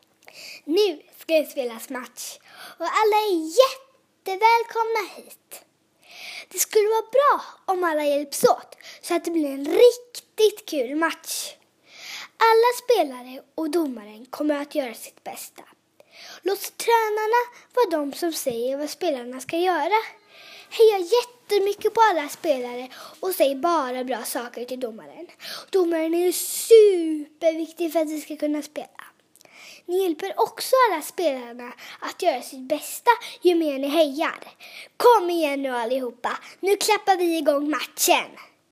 Introjingel barnmatch.m4a